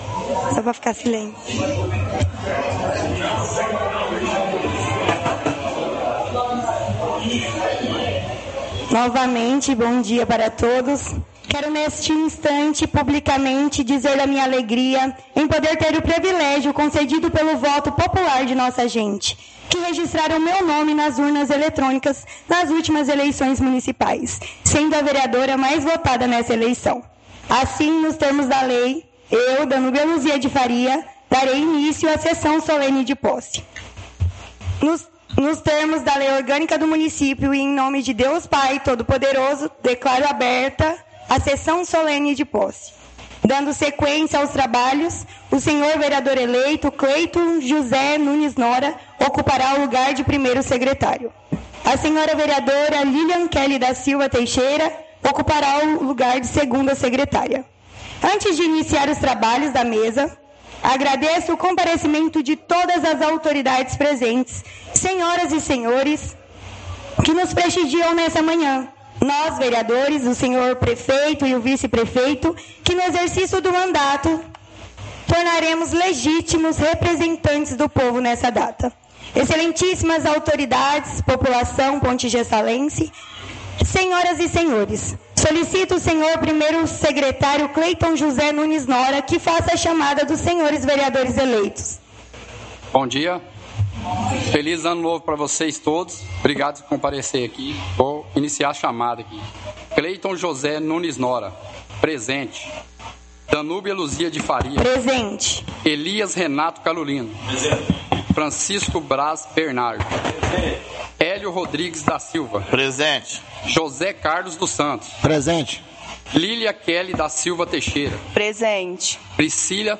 1ª Sessao Solene 01/01/2025 - Posse e Eleição Mesa